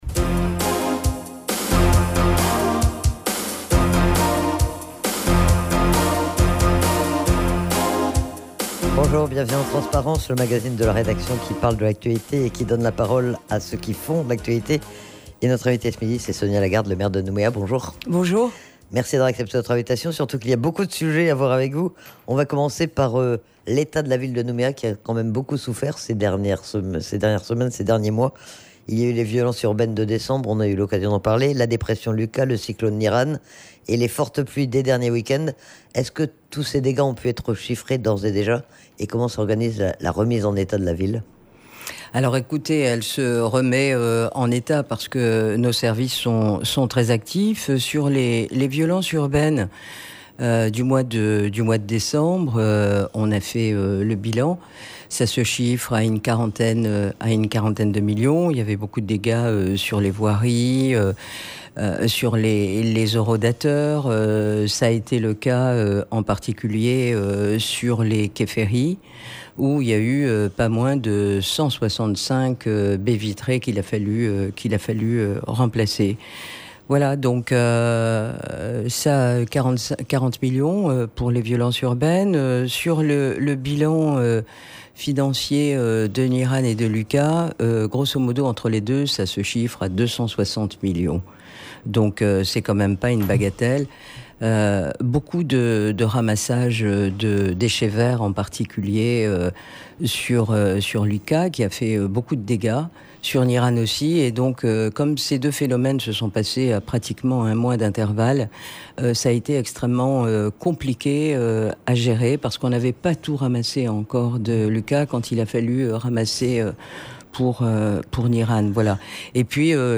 Sonia Lagarde est interrogée sur les conséquences des différents dégâts occasionnés par les événements météorologiques des derniers mois, sur les priorités de son budget 2021, sur les projets en cours, mais aussi sur son analyse de la situation politique actuelle.
Menu La fréquence aux couleurs de la France En direct Accueil Podcasts TRANSPARENCE : 23/04/21 TRANSPARENCE : 23/04/21 23 avril 2021 à 02:12 Écouter Télécharger Le maire de Nouméa, Sonia Lagarde est l'invitée du magazine Transparence.